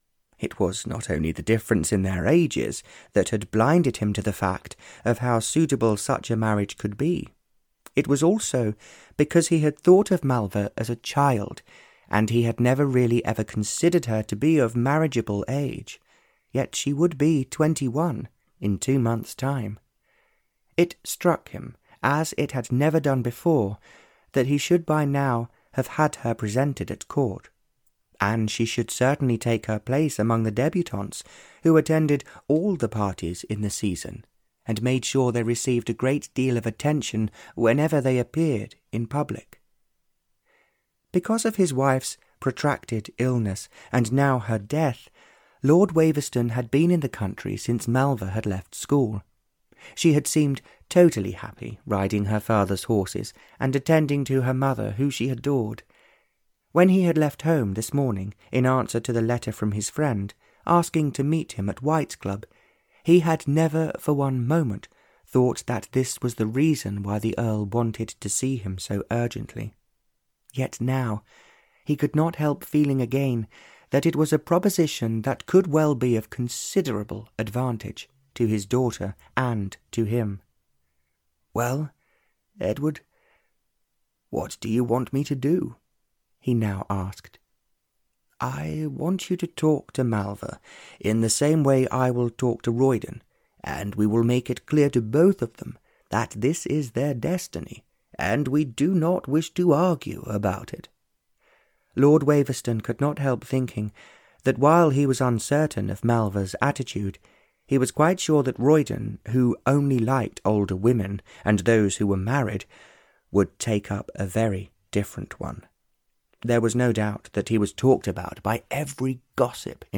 Danger in the Desert (Barbara Cartland's Pink Collection 110) (EN) audiokniha
Ukázka z knihy